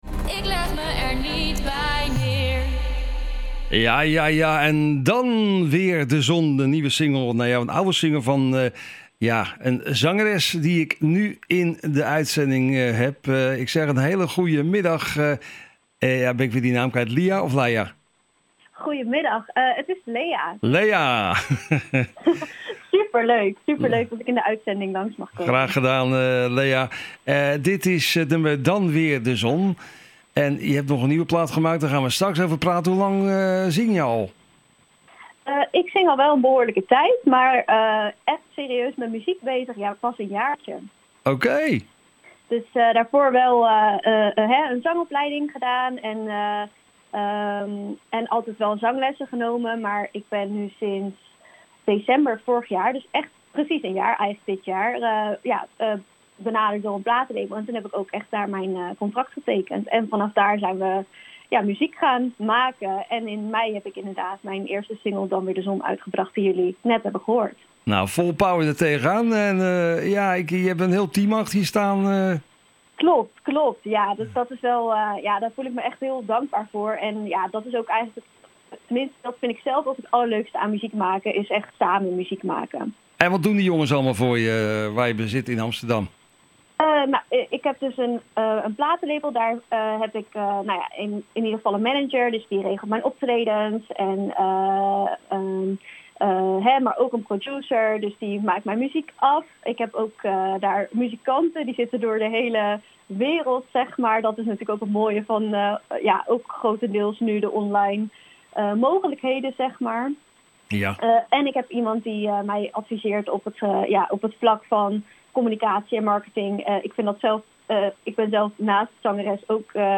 Hierbij werd ze ook geinterviewd. Luister het interview hier na, met tips voor de kerstfreak: